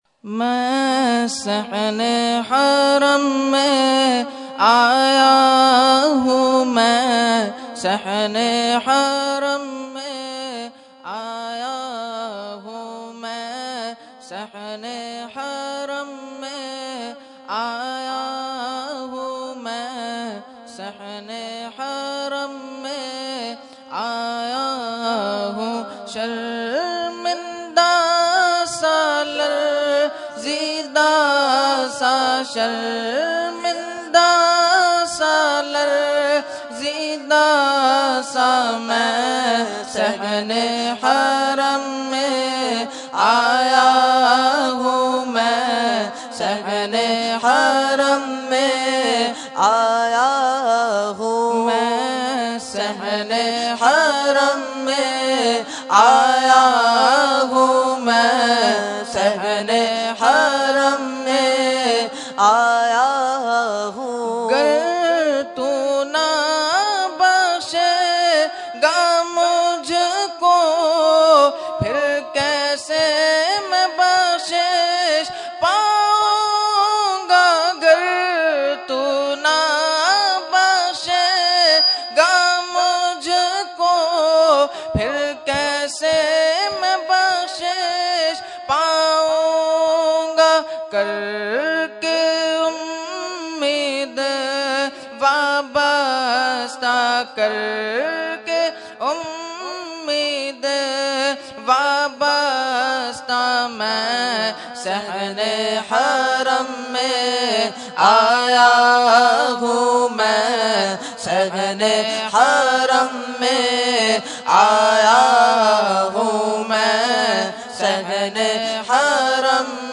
Category : Naat | Language : UrduEvent : Mehfil 11veen North Nazimabad 31 March 2014